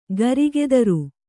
♪ garigedaru